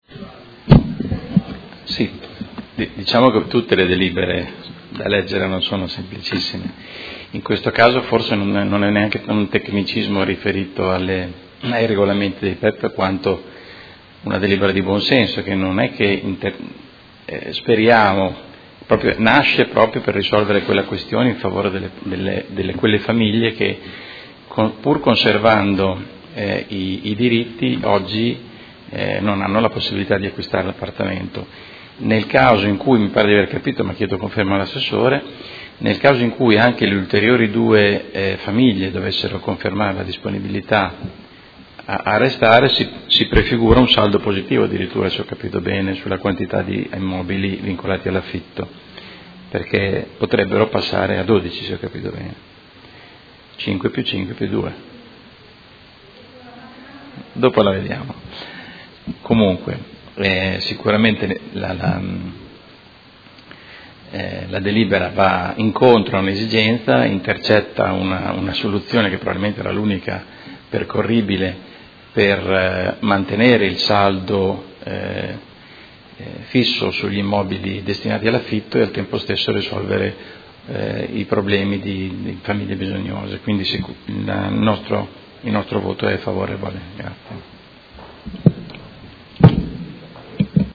Seduta del 20/07/2017 Dibattito. Alloggi con vincolo di locazione a termine nel P.E.E.P. “Panni”, lotto 7 – Scadenza del vincolo di destinazione alla locazione - Apposizione di nuovo vincolo decennale in sostituzione o trasferimento dell’obbligo di locazione a termine da un ugual numero di alloggi nel Comparto “Ghiaroni” - Accoglimento e condizioni